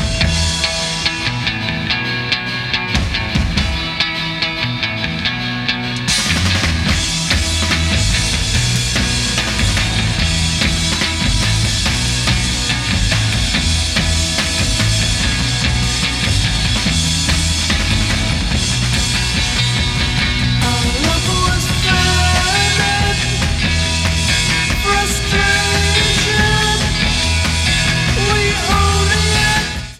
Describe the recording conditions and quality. Excellent reproduction of the mono mixes.